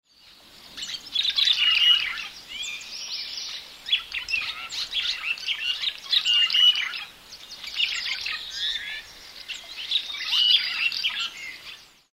Red-rumped Swallow  Cecropis daurica
Sonogram of Red-rumped Swallow song
Salto del Gitano, Monfragüe, Extremadura, Spain  39° 51' 36.0" N  05° 56' 28.6" W  18 June 2013
Song from a perched bird.